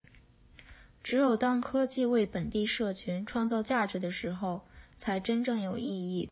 [ComfyUI]Index-TTS2声音生成克隆王者登基：影视级别效果—零样本克隆+情绪控制，重塑配音新体验
IndexTTS2是一款备受瞩目的新一代文本转语音（TTS）大模型，它以其能达影视级水准的高质量语音合成效果而引人注目。